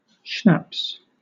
Ääntäminen
IPA : /ʃnɑps/ IPA : /ʃnæps/